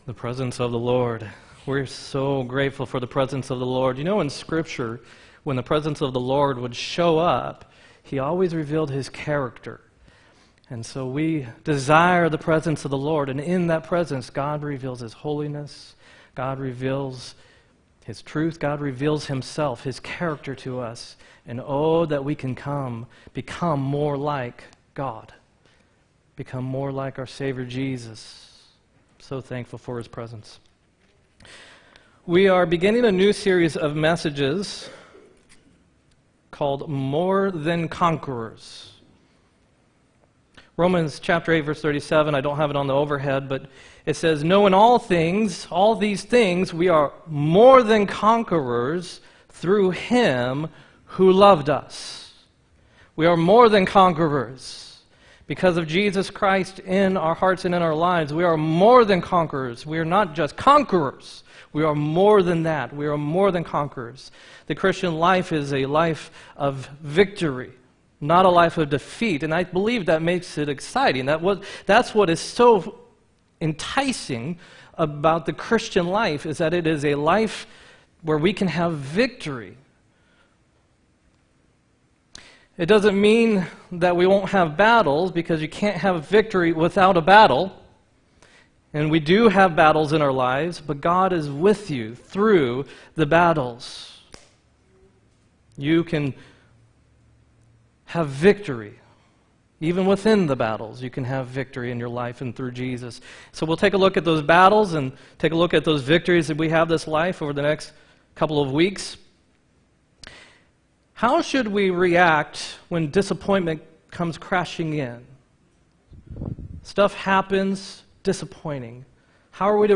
4-14-18 sermon